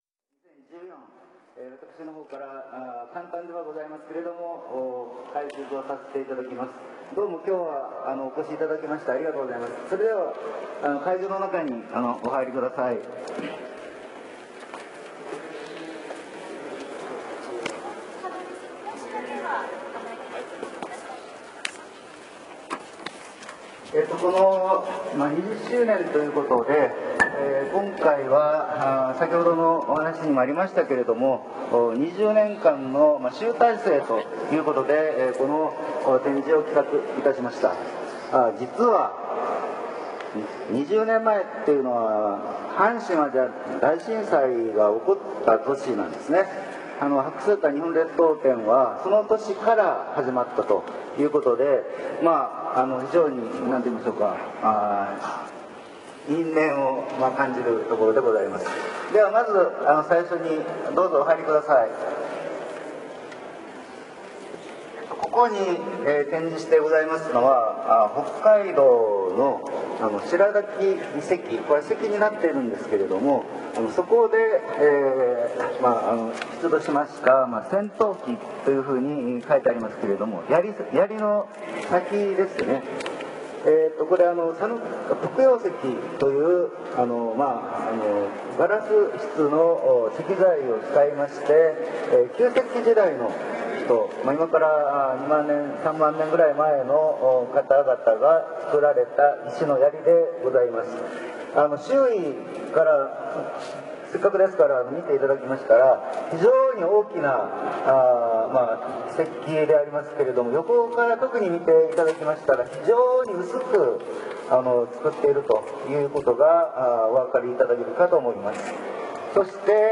展示解説 展示の見どころを学芸員の解説とともにめぐります。